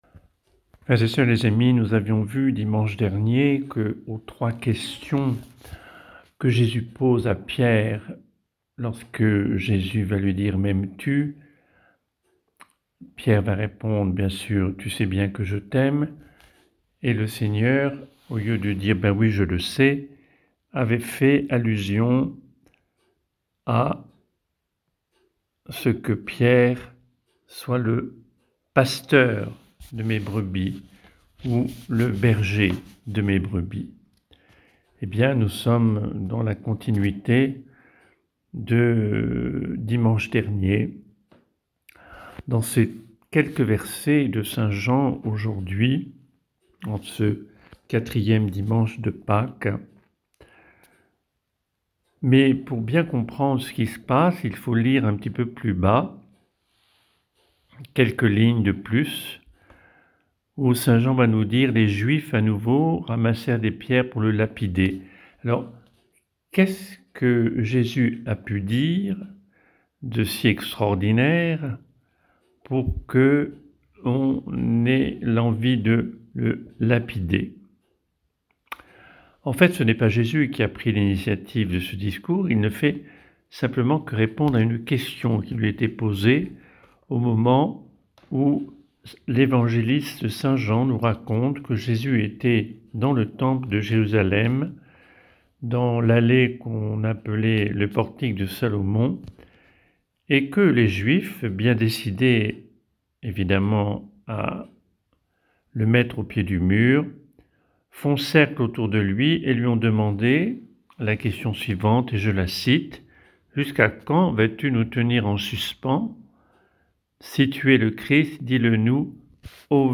Méditation